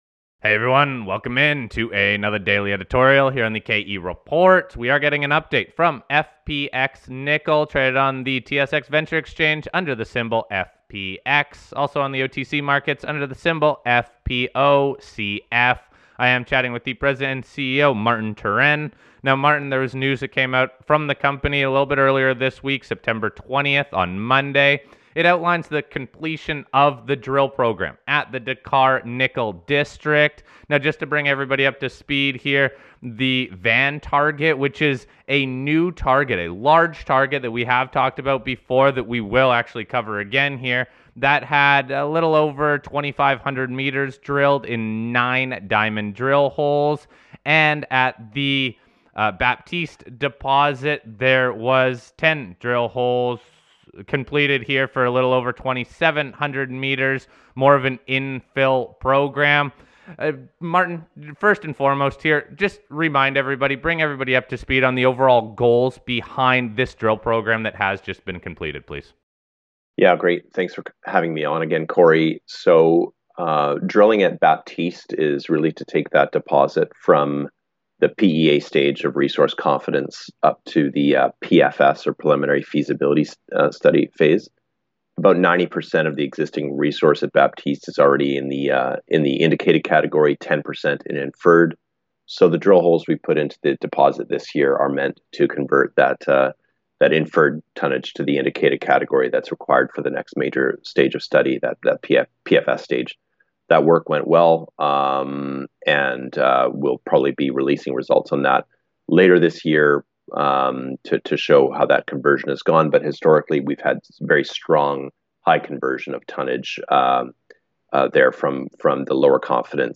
The program was focused on 2 key areas, the Baptiste Deposit and the Van Target. While the Baptiste Deposit is touted as the largest undeveloped nickel project in the world, the Van Target was previously never drilled and has large scale potential. We wrap up the interview with some comments on the marco nickel market and recent news out of Indonesia (a key supplier of the world’s nickel).